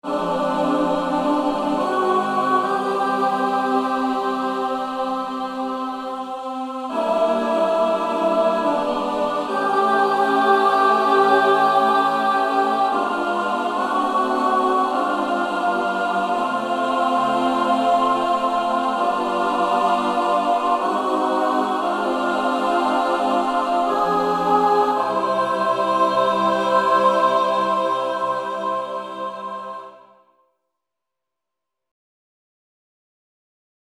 Key written in: C Major
How many parts: 4
Type: Barbershop
End can be a screamer!
All Parts mix: